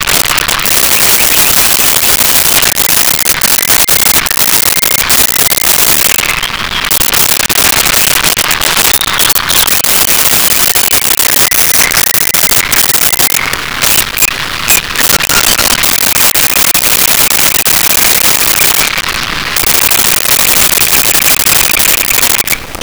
Purple Sandpipers Chirping
Purple Sandpipers Chirping.wav